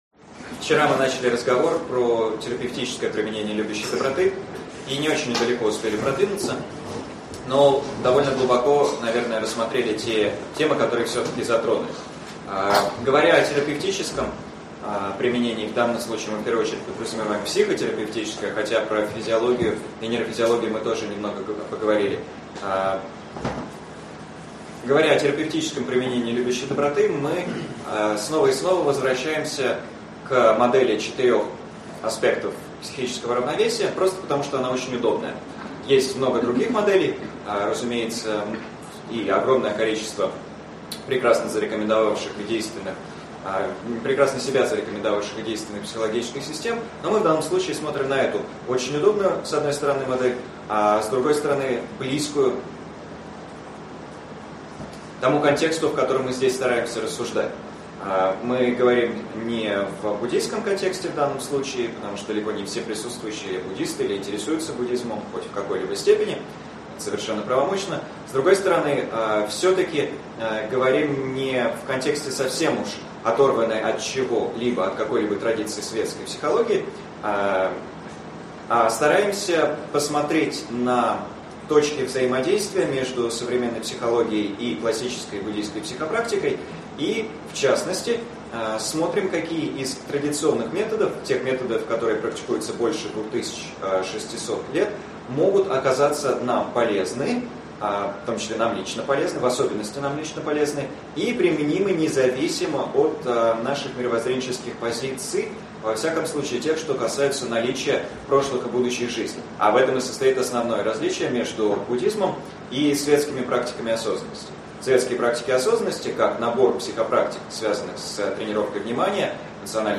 Аудиокнига Терапевтическое применение практики любящей доброты. Часть 4 | Библиотека аудиокниг